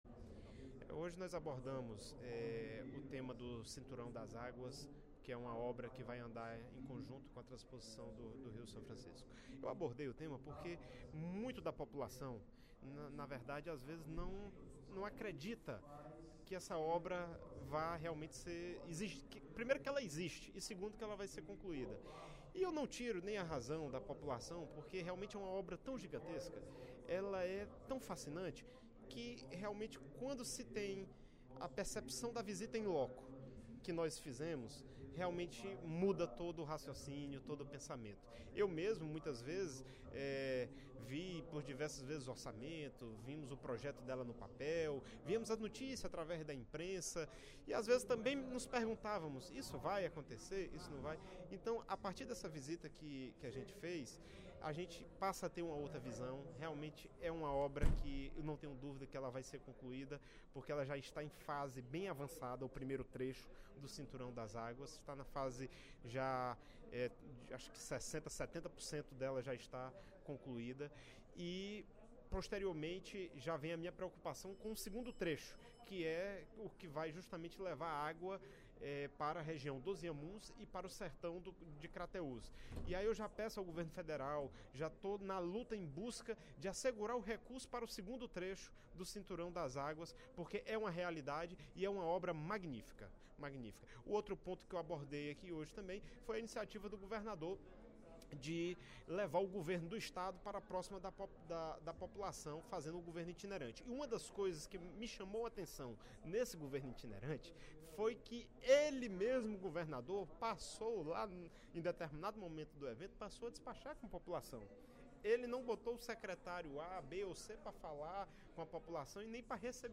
O deputado Joaquim Noronha (PP) relatou, durante o primeiro expediente da sessão plenária desta terça-feira (02/06), a visita feita há uma semana às obras da Transposição do São Francisco.
Dep. Joaquim Noronha (PP) Agência de Notícia da ALCE